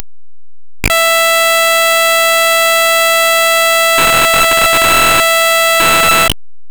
Van 4 egyforma szélességű, üresség, egy fél szélességű majd 13 egyforma szélességű, újabb üresség, aztán 4 pont ugyanolyan, az elején.
Az a régi htp2wav konverterrel készült. Nálam le sem játszható, hibás még a wav formátuma is.